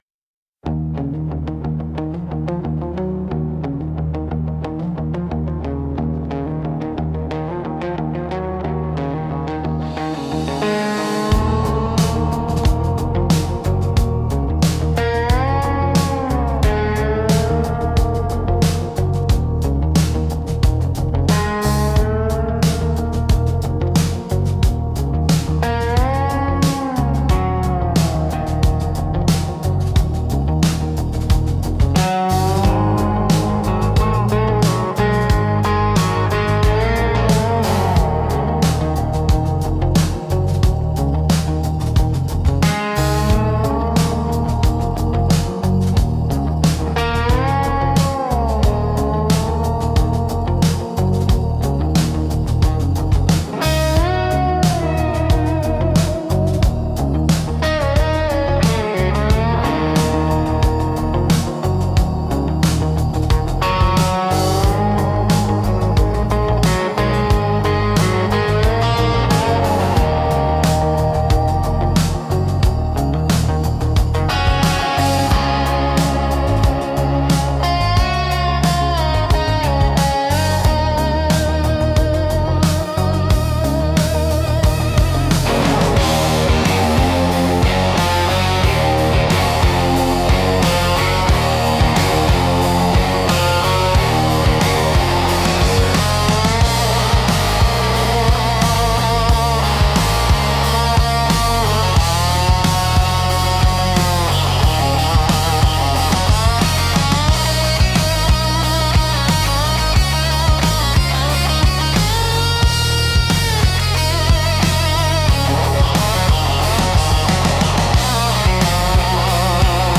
Instrumental - Dust-Devil Slide 3.21